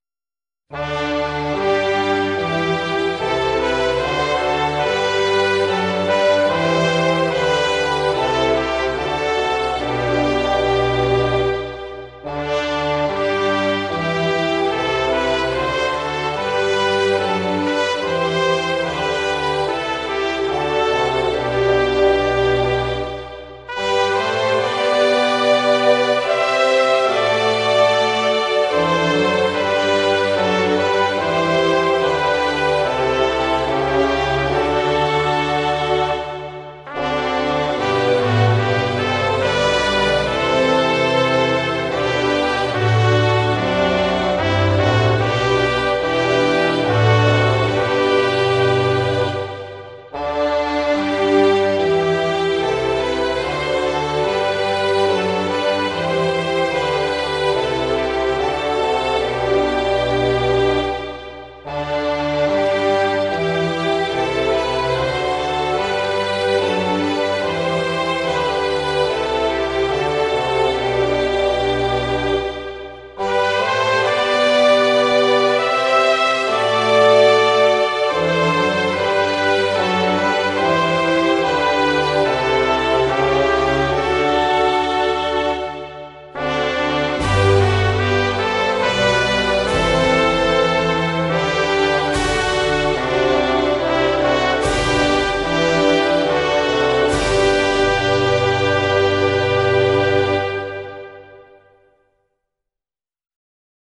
Volkslied Wilhelmus van Nassouwe (instrumentaal 1 couplet)